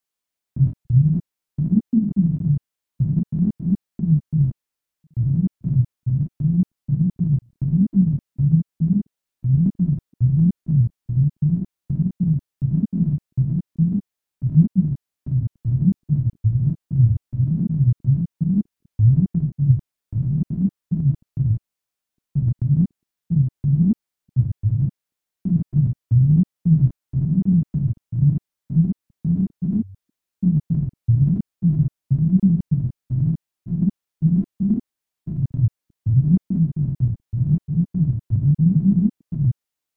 However, here's an eduction of the pitch contours exactly as they appear on the page:
(Actually, I made a mistake; instead of line 2, I played line 3 twice in a row.